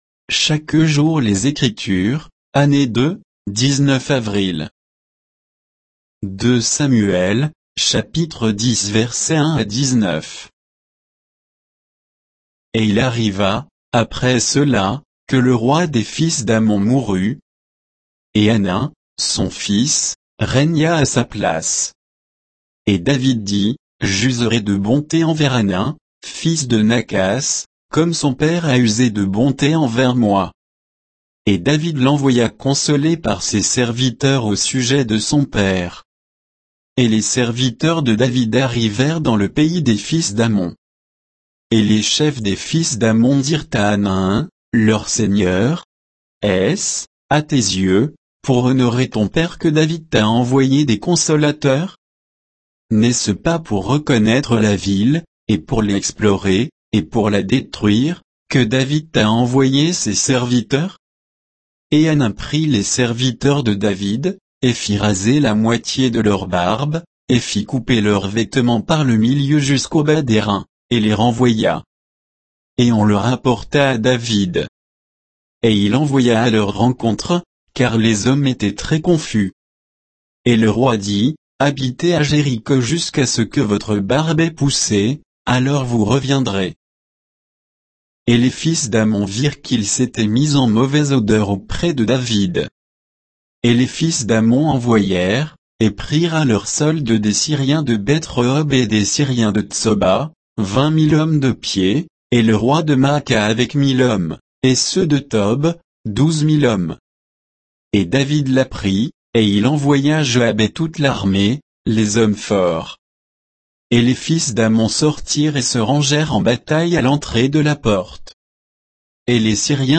Méditation quoditienne de Chaque jour les Écritures sur 2 Samuel 10